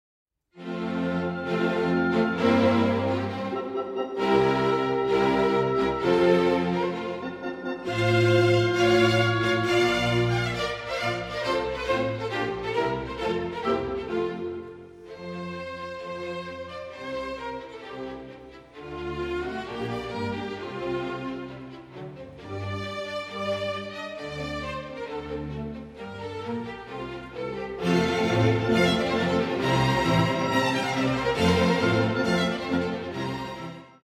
Allegro 7:40